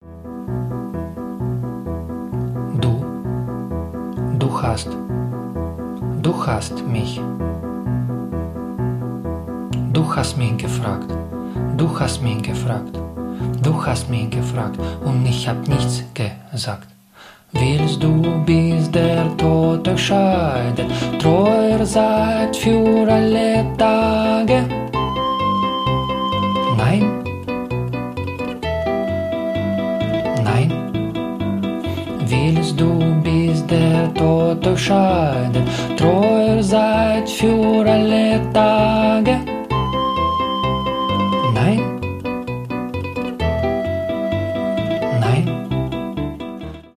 укулеле спокойные
акустика